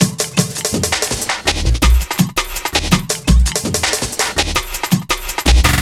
Index of /90_sSampleCDs/Zero-G - Total Drum Bass/Drumloops - 2/track 27 (165bpm)